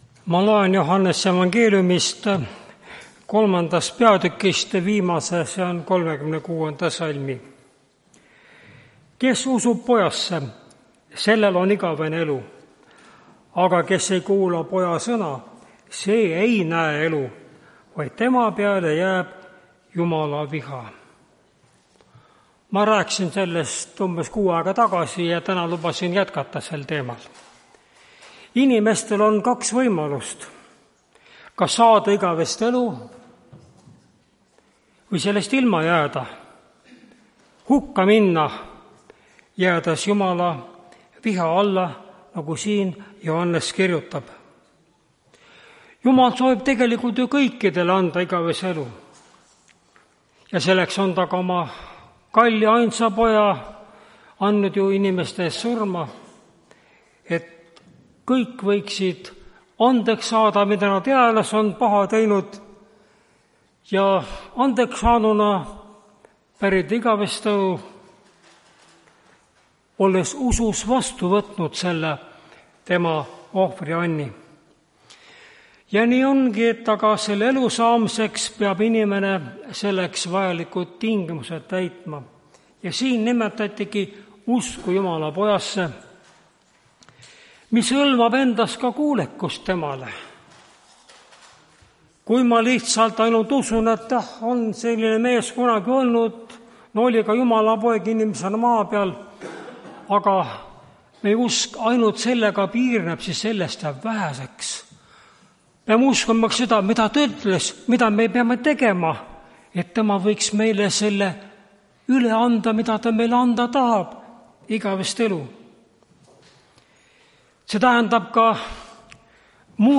Tartu adventkoguduse 10.08.2024 hommikuse teenistuse jutluse helisalvestis.